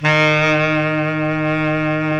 SAX B.SAX 06.wav